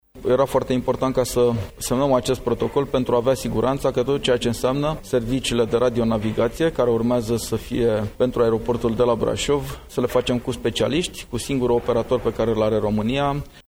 Preşedintele Consiliului Judeţean Braşov, Adrian Veştea: